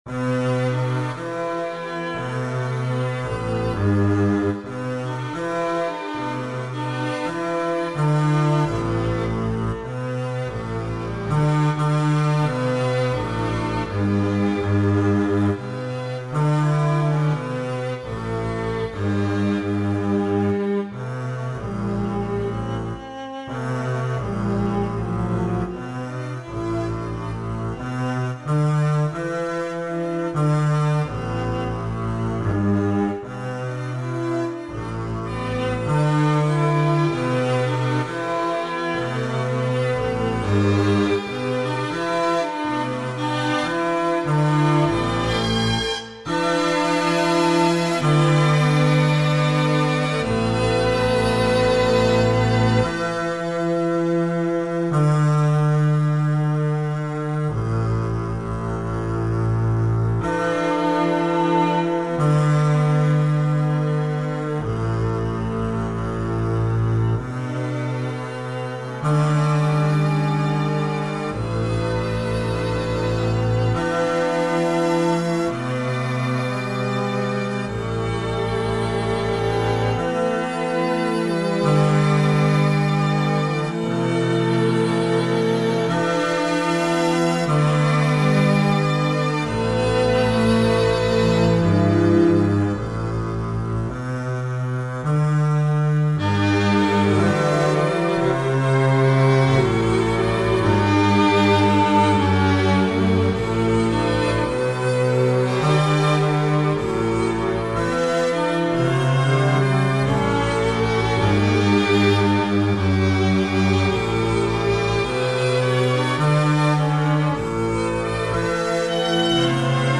String quartet.